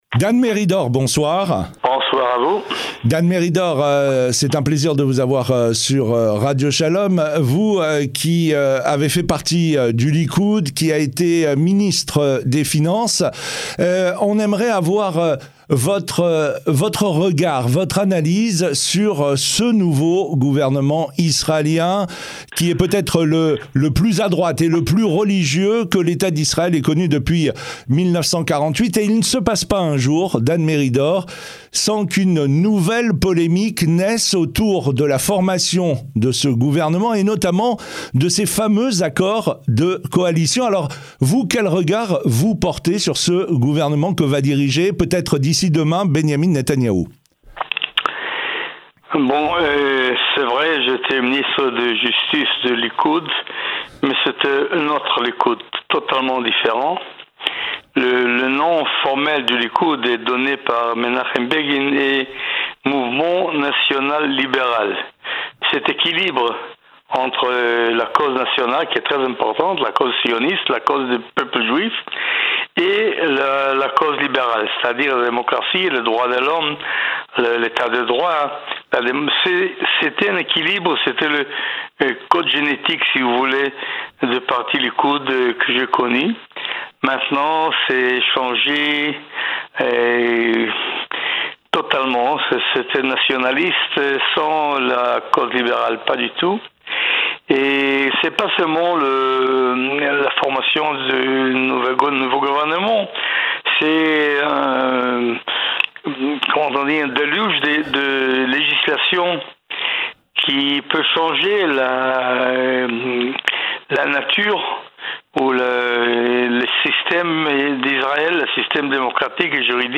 Dan Meridor, ancien ministre de la Justice dans le gouvernement d'Yizthak Shamir et ancien député du Likoud s'exprime sur la formation du nouveau gouvernement israélien dirigé par Benyamin Netanyahou ce jeudi 29 décembre 2022.